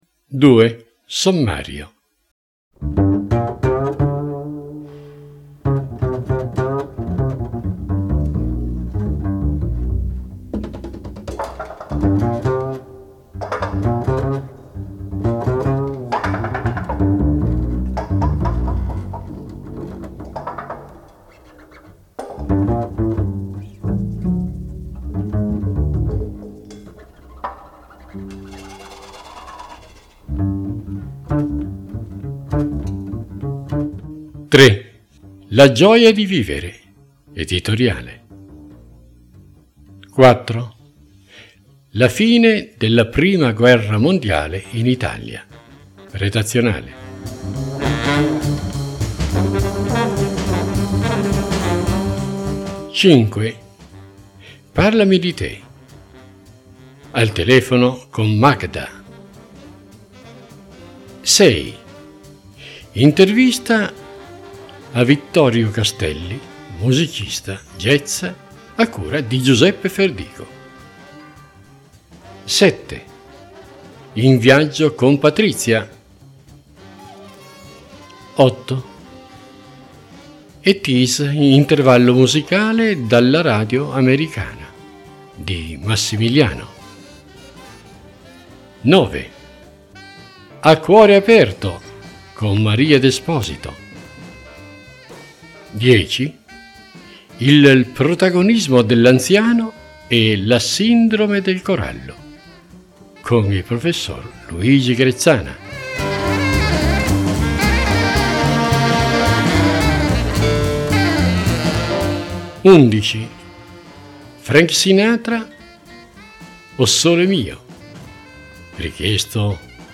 Il tutto nella cornice ditanti brani musicali senza tempo e per tutti i gusti, richiesti dai lettori.